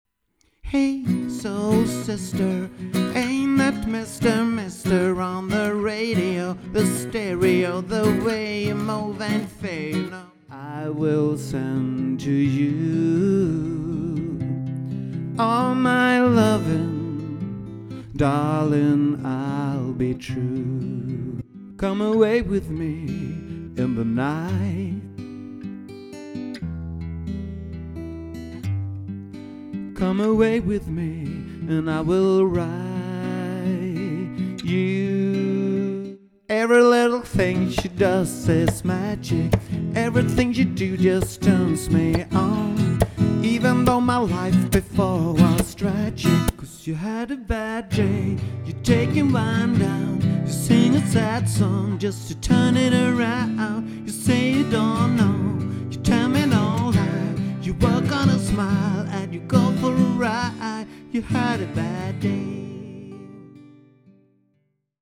Erfaren trubadur som spelar mingelmusik, covers och allsång.
• mingelmusik (Fördrink-medley)